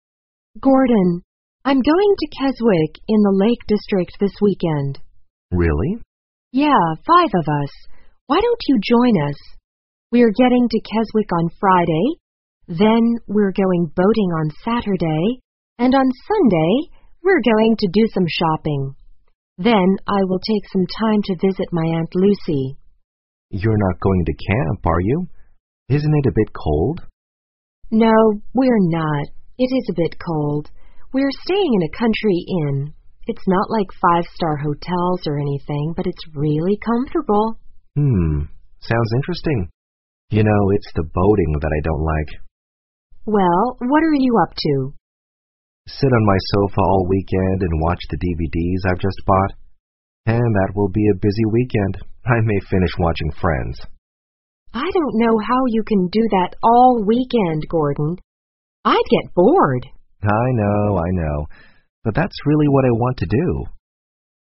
2016年高考(江苏卷)英语听力真题 长对话(2) 听力文件下载—在线英语听力室